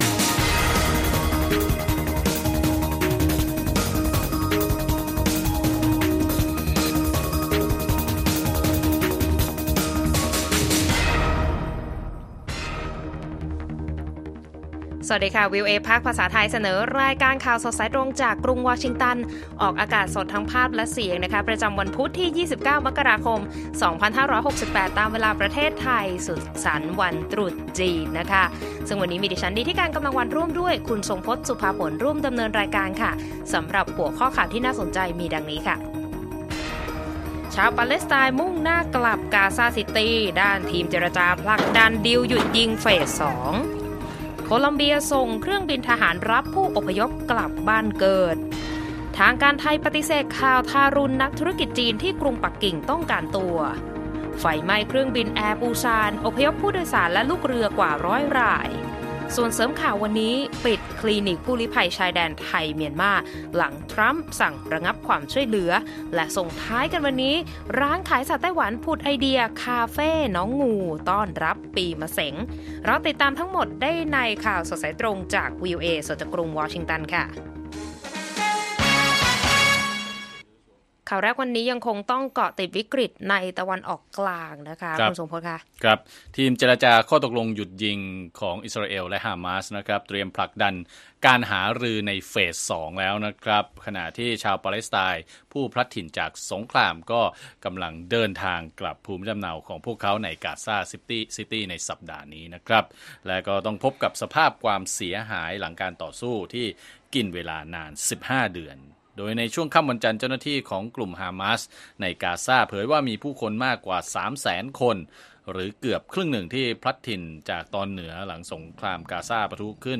ข่าวสดสายตรงจากวีโอเอ ภาคภาษาไทย พุธ ที่ 29 มกราคม 2568